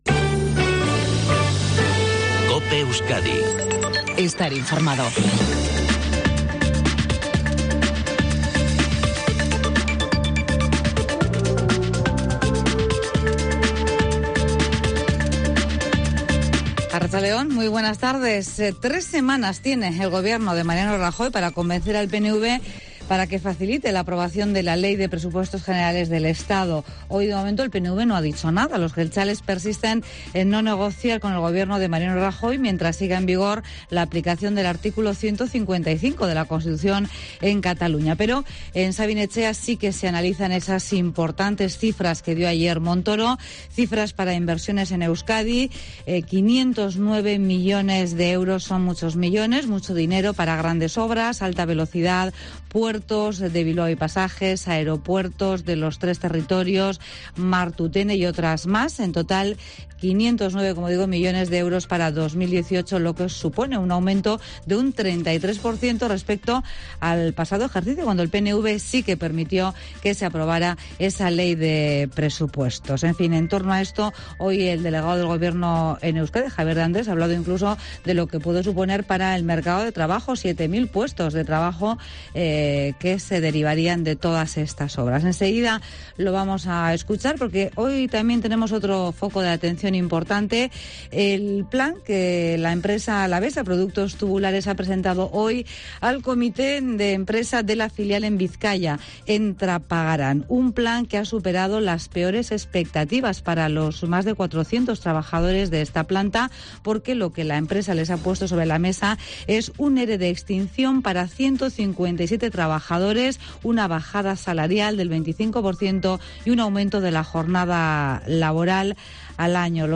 INFORMATIVO EUSKADI MEDIODIA 14 20 A 14 30.mp3